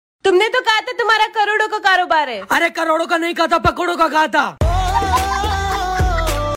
funny 😂 video sound effects free download